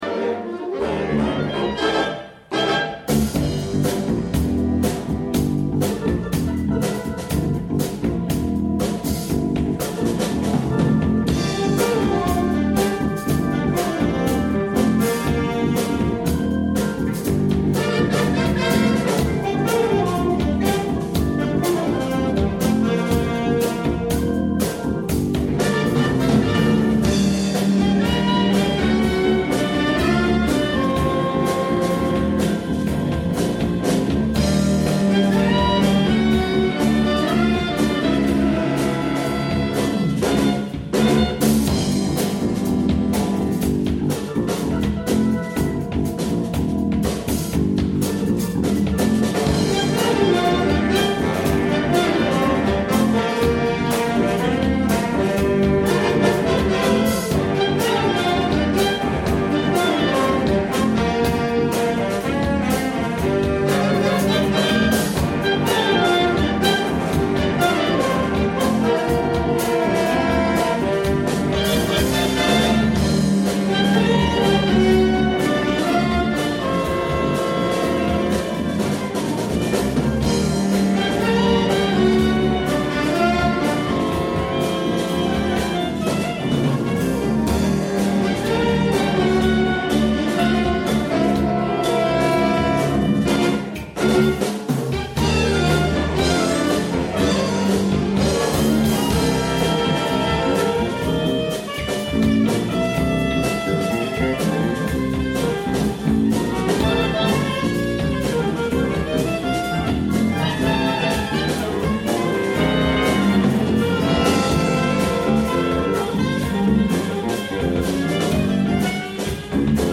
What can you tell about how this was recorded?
Summer Concert 2014